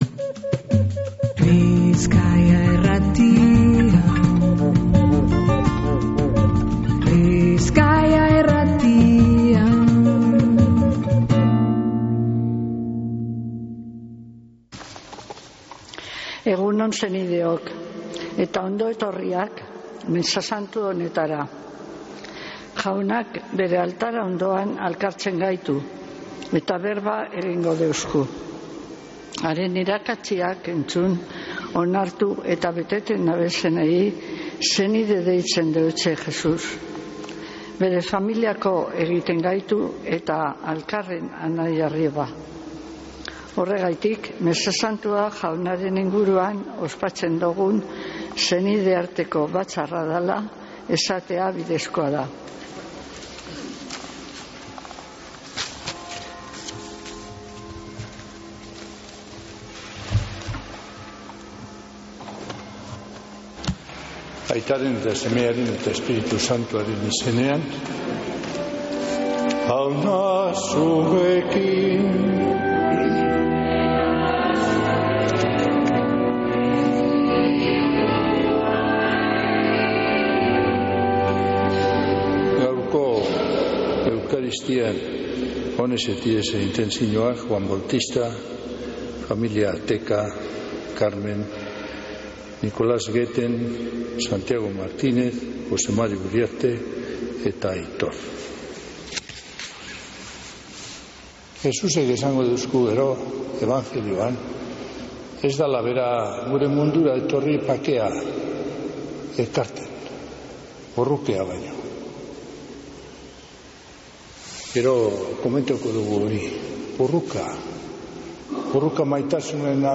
Mezea